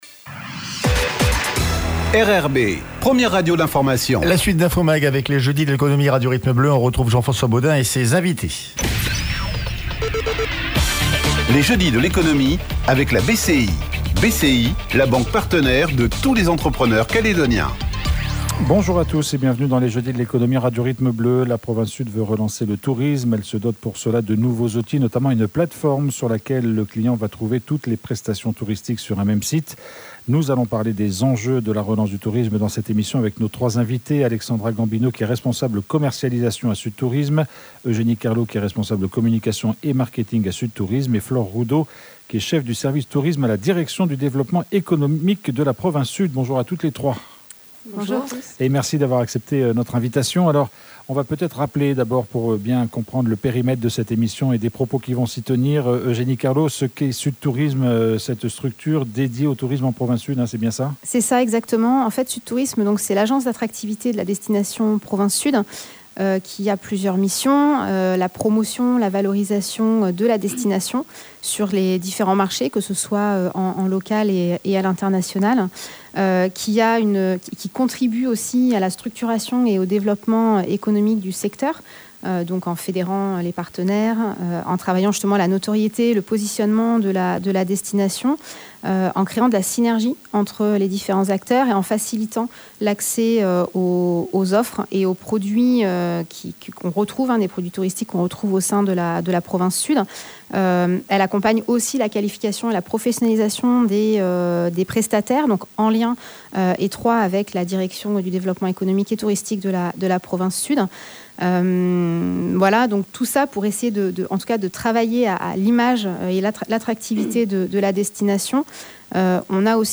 La province sud veut relancer le tourisme. Elle vient de créer une plate-forme - une market place - sur laquelle le client va trouver toutes les prestations touristiques, sur un même site. Nous en parlerons demain dans les Jeudis de l’Economie Radio Rythme Bleu avec nos trois invitées